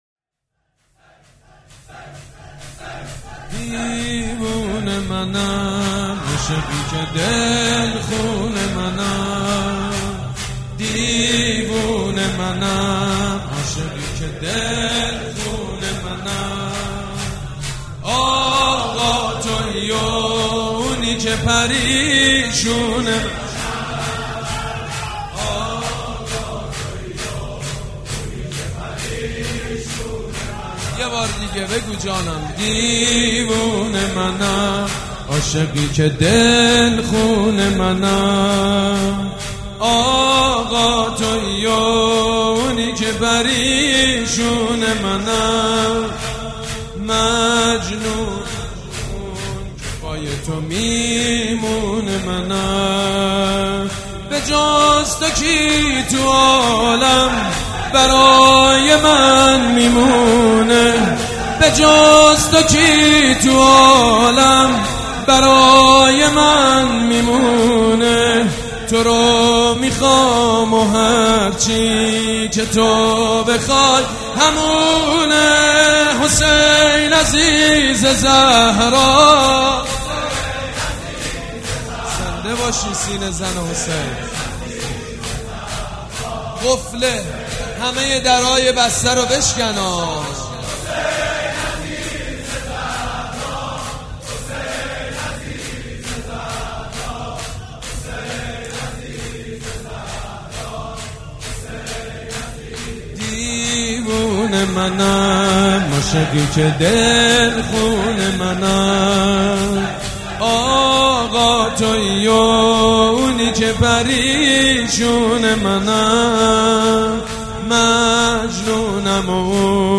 شور
مداح
حاج سید مجید بنی فاطمه
شهادت حضرت مسلم علیه السّلام